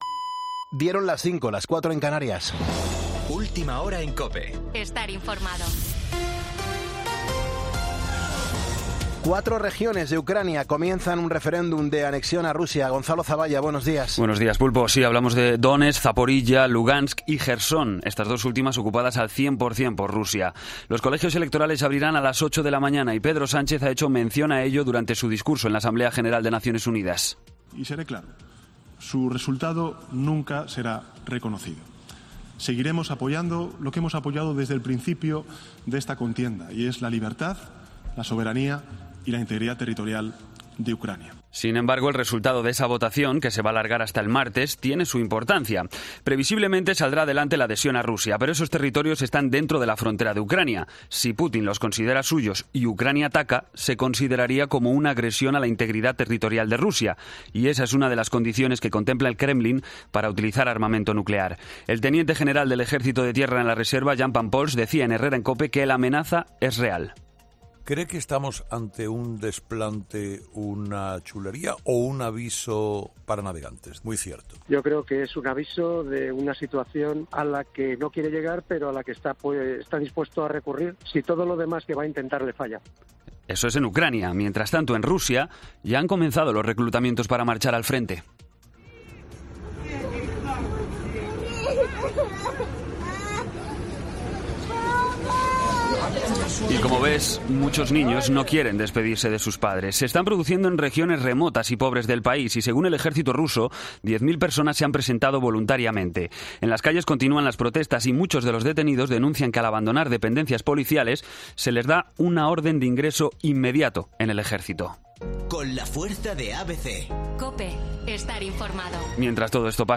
Boletín de noticias COPE del 23 de septiembre a las 05:00 hora
AUDIO: Actualización de noticias Herrera en COPE